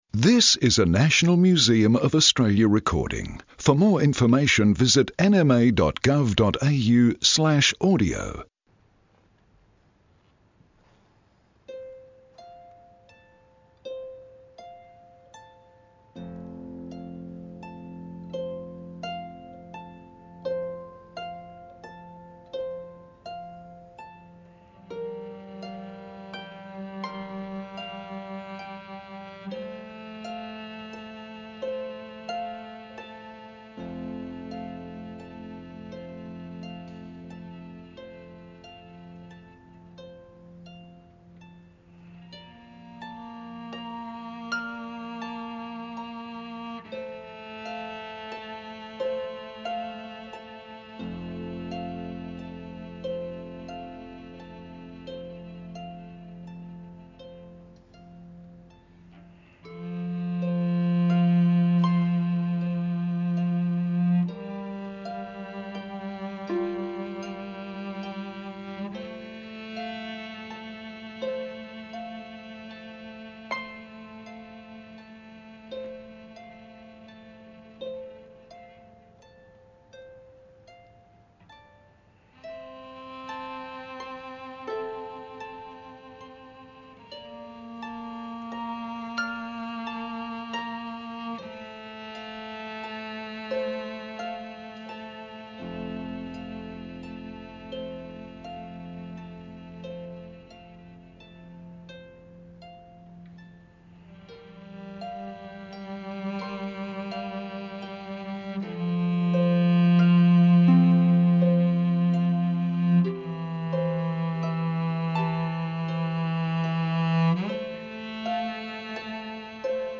cellist